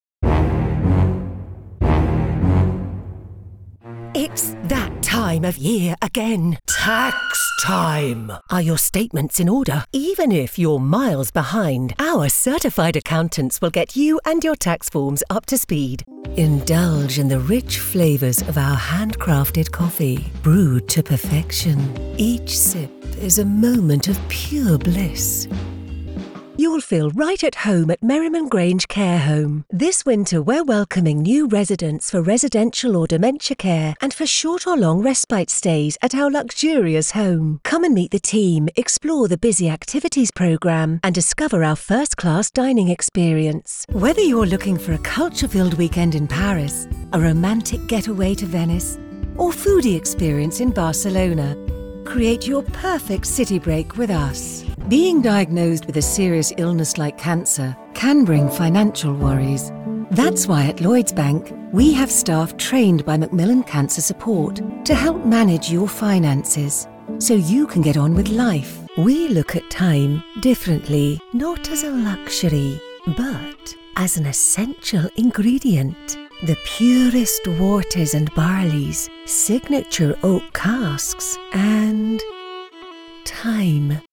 Velvety, smooth and sophisticated UK voice actor with a multitude of character voices!
Commercial Demo